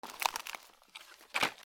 古びた木片 物音 ゴソゴソ
/ M｜他分類 / L01 ｜小道具 /
『ミシ カラ』